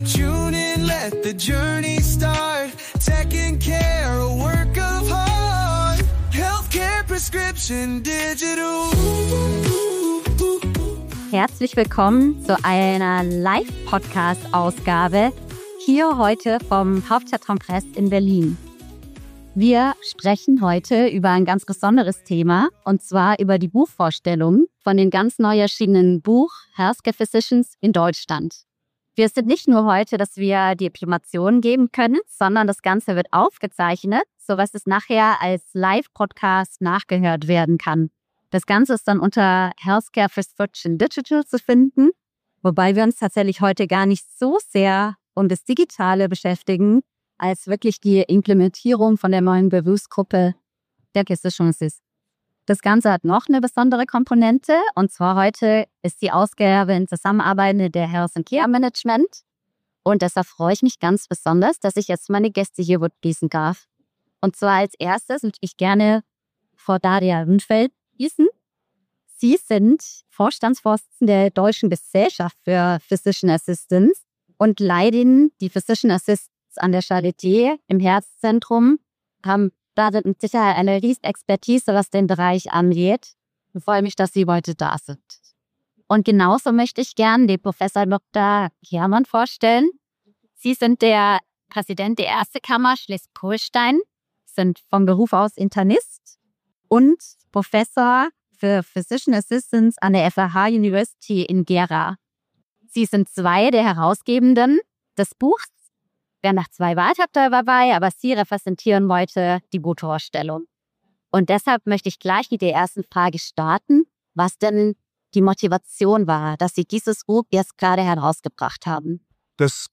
Live Podcast vom HSK 2025 - Physician Assistants in Deutschland ~ Healthcare Prescription: Digital Podcast
In dieser Live-Ausgabe von Healthcare Prescription: Digital vom Hauptstadtkongress in Berlin dreht sich alles um die Etablierung des Berufsbildes der Physician Assistants (PA).
Gemeinsam sprechen wir über Motivation und Zielsetzung des Buches, internationale Vorbilder, aktuelle rechtliche Rahmenbedingungen, praxisnahe Beispiele aus der Versorgung sowie die Rolle von Digitalisierung, Telemedizin und KI für Physician Assistants. Ein inspirierendes Gespräch über interprofessionelle Zusammenarbeit, gesundheitspolitische Verantwortung und neue Wege für eine zukunftsfähige medizinische Versorgung in Deutschland.